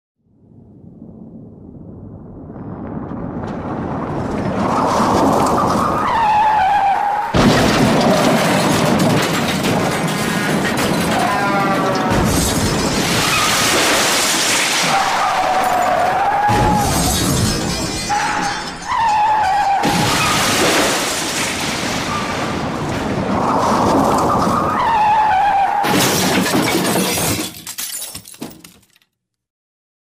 Car Crash Effect